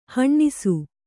♪ haṇṇisu